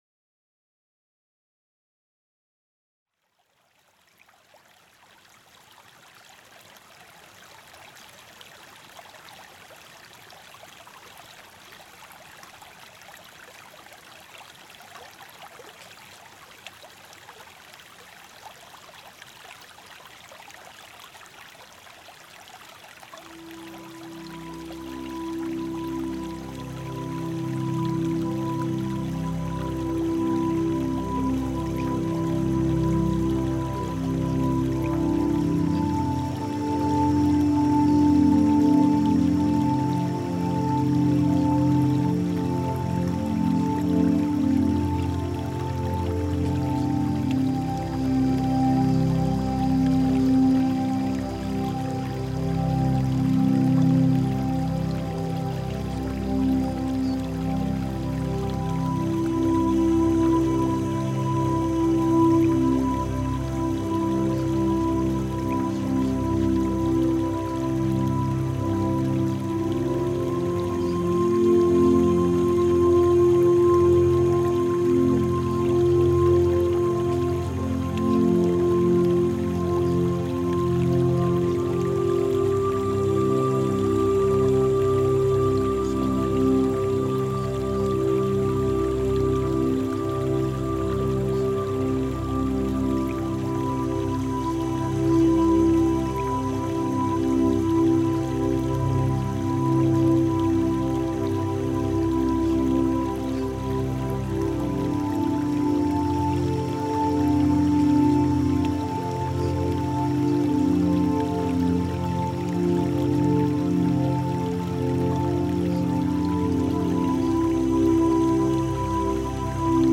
INSTRUCTIONS Subliminal Activation sessions embed the mental programming script inside subliminal technology. This script is hidden behind relaxing music.
You may hear fragments of words from time to time.
SUBLIMINAL+-++AM+Module+9+-+Money+Likes+Attention.mp3